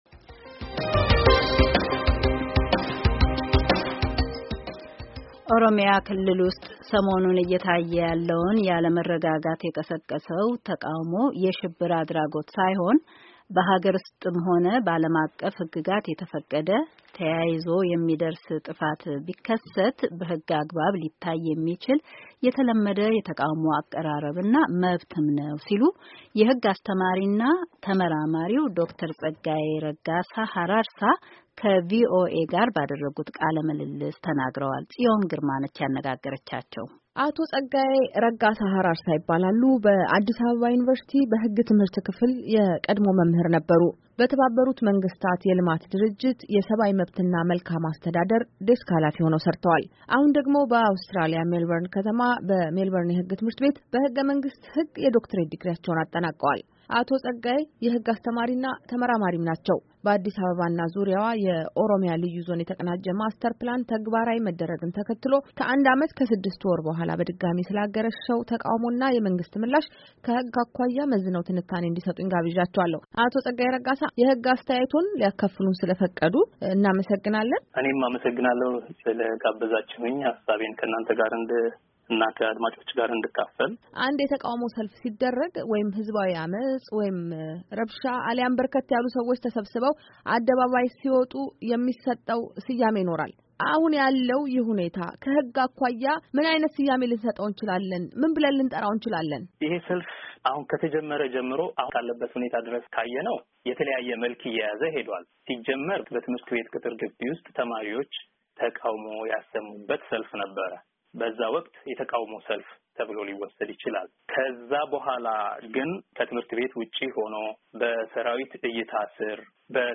ቃል ምልልስ